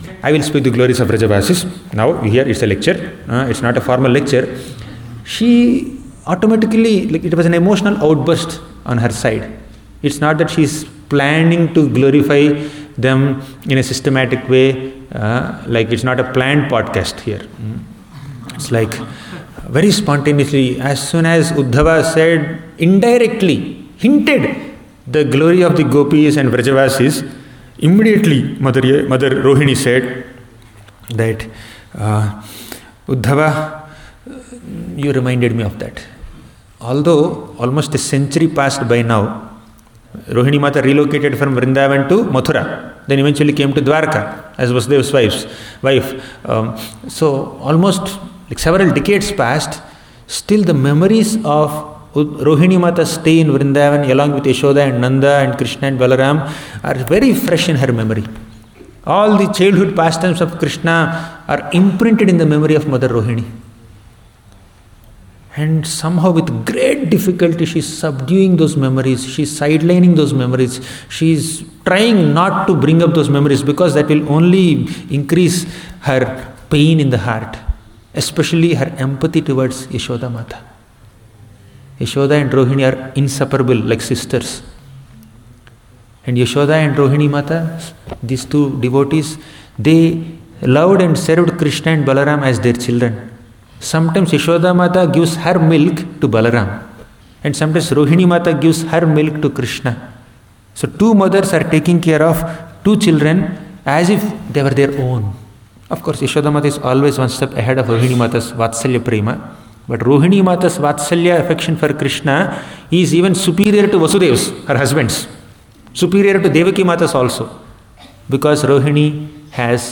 Saranagati Retreat - Brhad Bhagavatamrta 5 - a lecture
Govardhana Retreat Center